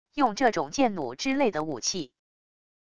用这种箭弩之类的武器wav音频